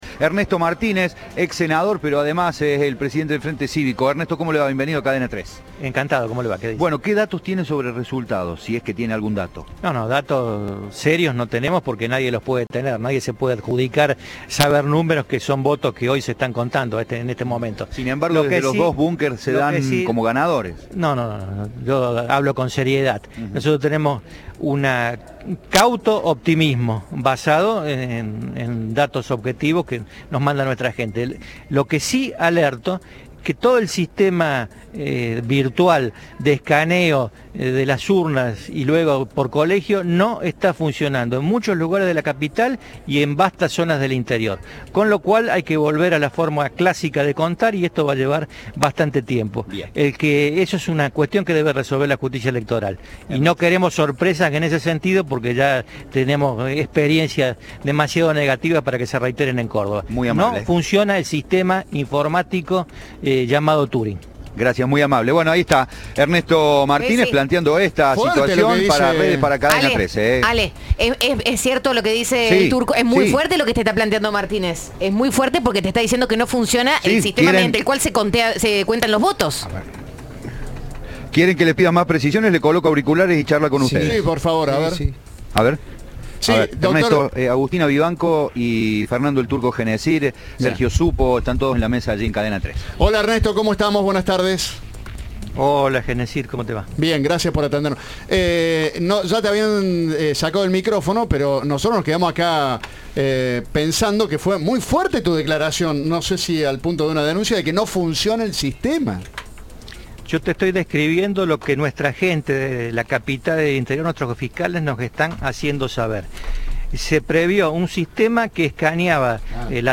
El presidente del Frente Cívico y ex senador, Ernesto Martínez, denunció en diálogo con Cadena 3 aseguró que el sistema de conteo de votos "está fallando en numerosas escuelas", tanto de Córdoba capital como del interior provincial.